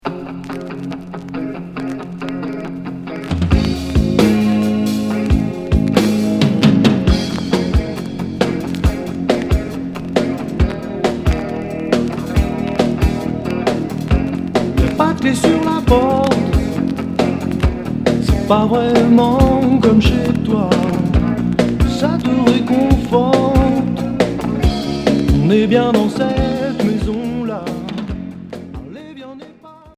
Rock FM